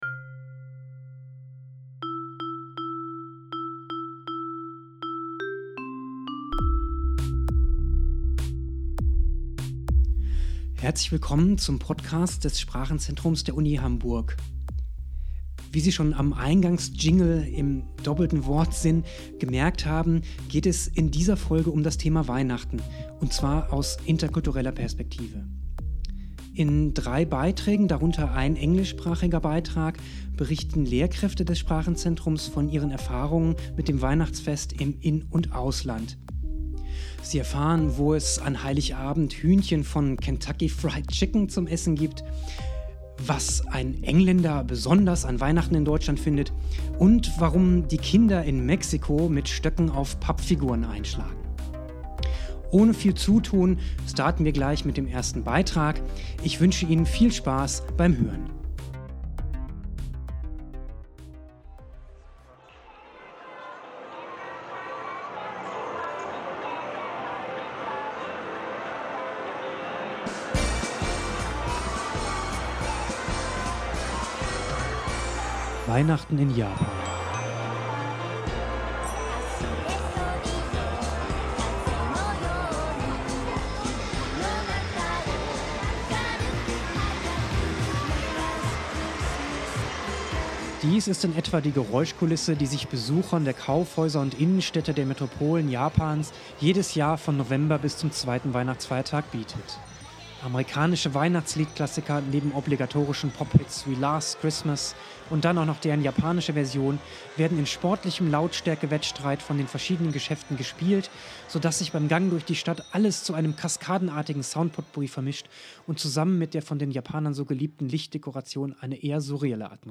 Folge 2: Weihnachten in verschiedenen Ländern (MP3 - 29,7 MB) In dieser Weihnachtsfolge berichten einige Lehrkräfte des Sprachenzentrums über das Weihnachtsfest in aller Welt. Sie erfahren viele spannende Details über die weihnachtlichen Eigenheiten der vorgestellten Länder, unter anderem: wo es an Heiligabend Hühnchen von "Kentucky Fried Chicken" gibt, warum die Kinder in Mexiko mit Stöcken auf Pappfiguren schlagen und wie sich ein Engländer in der deutschen Adventszeit fühlt.